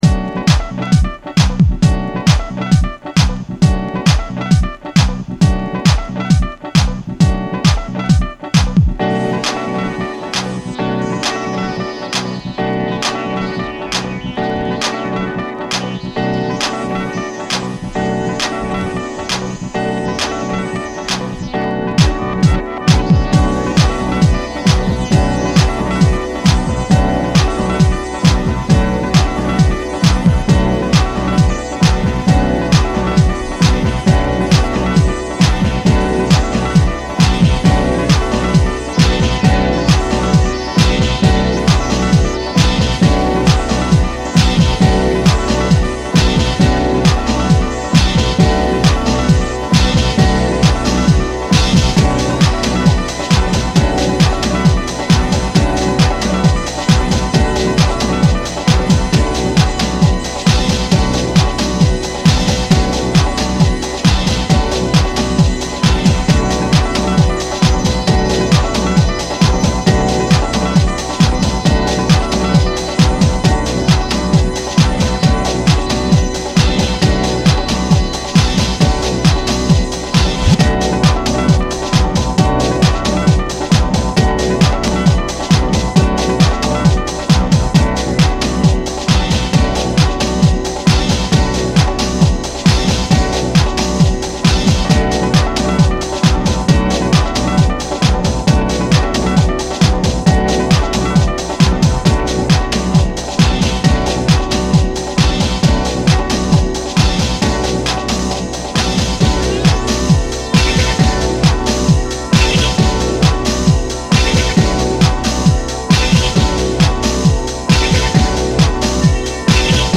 Long deleted 2004 re-issue of this Detroit classic.